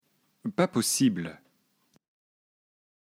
schwa_12.mp3